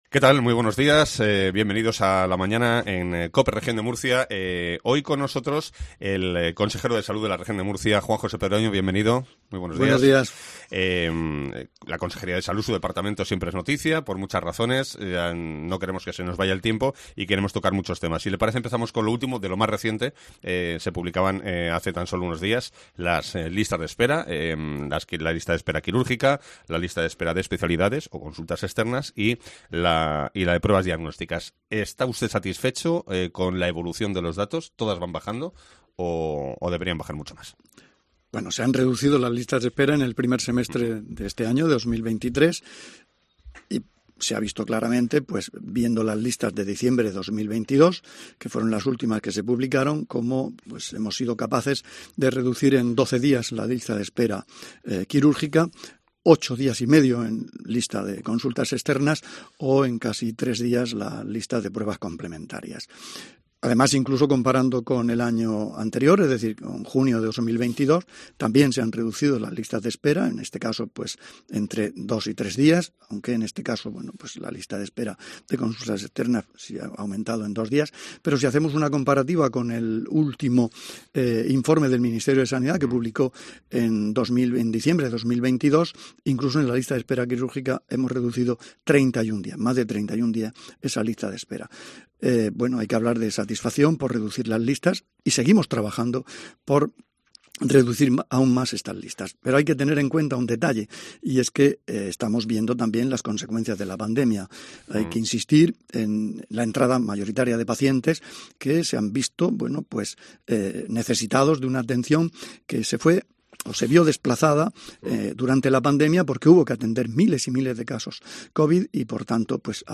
AUDIO: Juan José Pedreño, consejero de Salud, analiza en COPE Murcia las situación sanitaria en la Región de Murcia